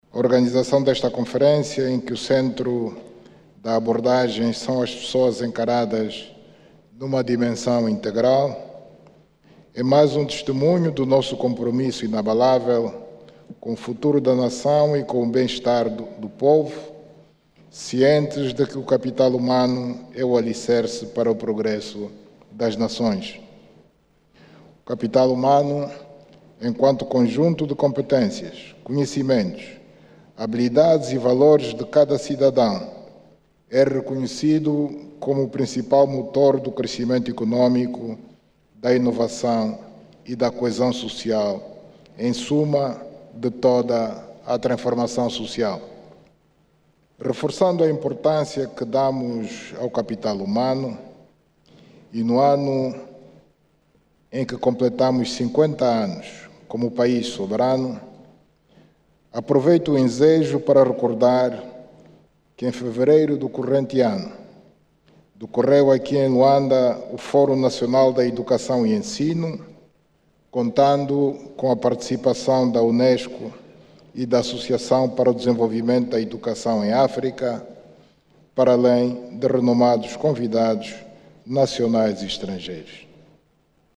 O Chefe de Estado fez estes pronunciamentos hoje, durante o discurso de abertura da 1.ª Conferência Nacional sobre o Capital Humano, que termina amanhã.